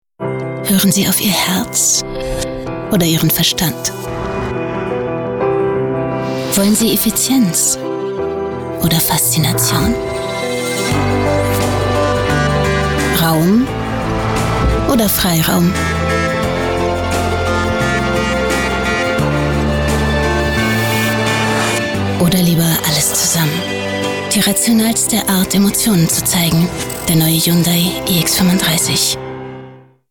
warm, lieblich, frisch, lebendig, hell, überzeugend, leicht
Sprechprobe: Werbung (Muttersprache):